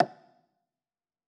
Wood Block1.wav